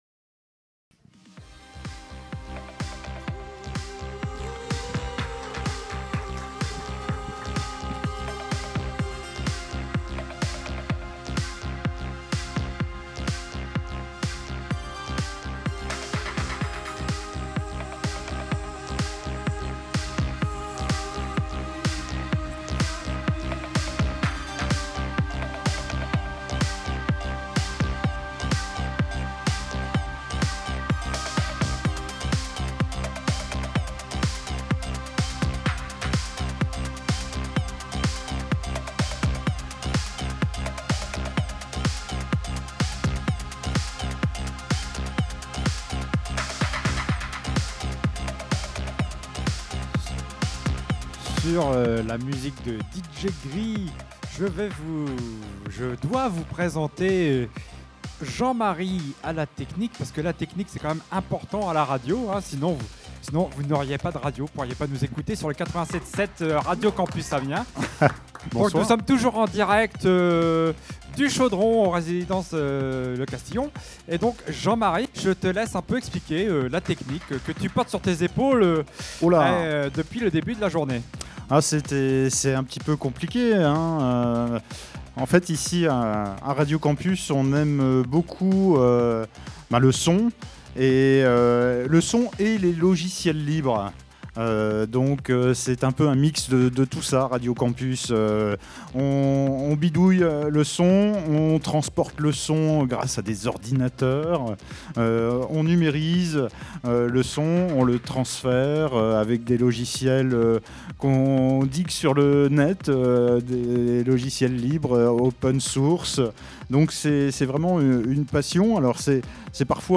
Samedi 22 mars, les animateurs et animatrices Radio Campus sur scène au Chaudron - Scène étudiante du Crous et en direct !
15-ans-avec-les-benevoles-linterview-de-la-technique.mp3